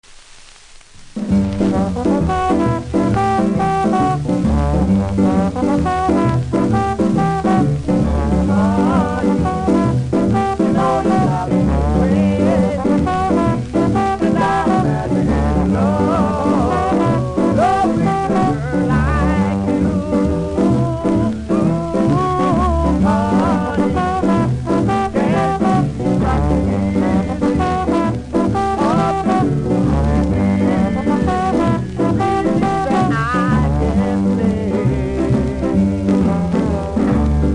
CONDITION：VG ( HISS )
キズによるノイズは少なめですがプレス起因のヒスあります。